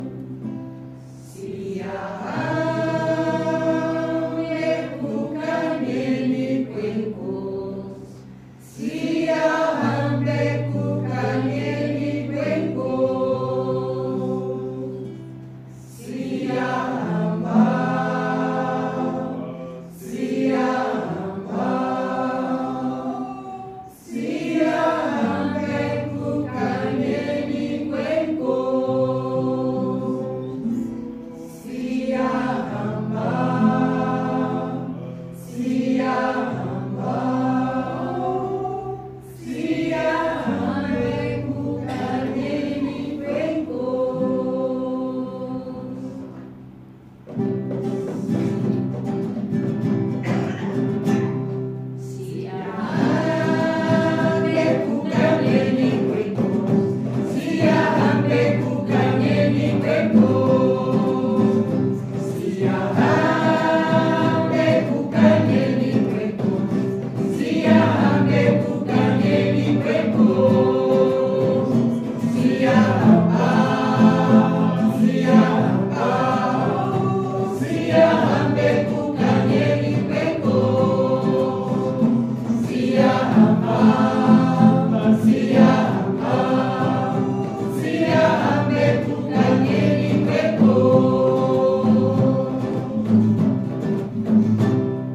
Quando a música une culturas: o Natal no Coro da UBI
O “Encontro de Músicas de Natal” organizado pelo Coro da UBI transformou, no dia 4 de dezembro, o Auditório da Biblioteca Central da universidade num espaço de celebração e partilha.
A noite juntou estudantes, antigos membros da comunidade académica e curiosos que quiseram ouvir o Natal noutras línguas, desde as músicas tradicionais portuguesas até aos ritmos africanos.
musica-coro.mp3